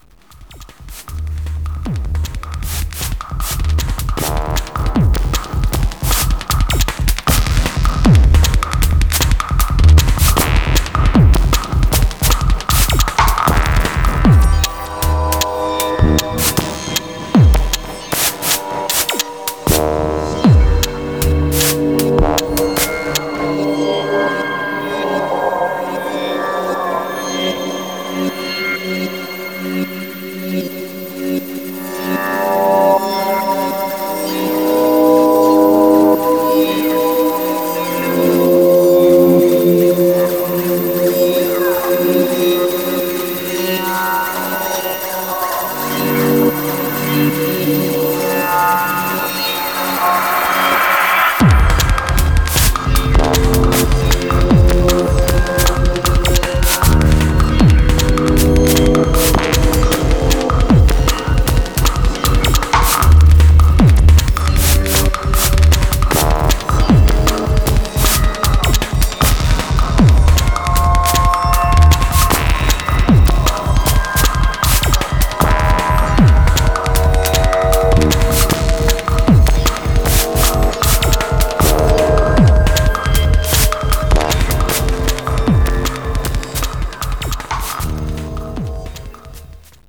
ハーフタイム、ポリリズムが錯綜、自動生成されてゆくような、テクノの何か得体の知れない領域が拡大しています。